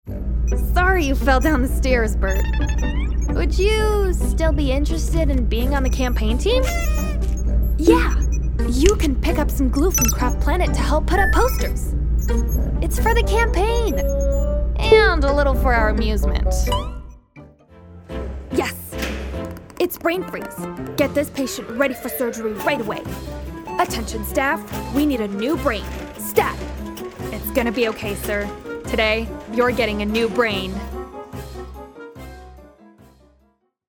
Animation (1) - EN